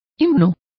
Complete with pronunciation of the translation of hymns.